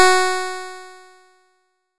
nes_harp_Fs4.wav